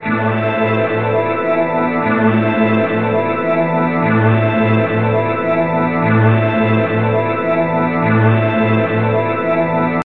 描述：一个欢快的管风琴，带着一丝丝环境和音景的声音。
Tag: 环境 声景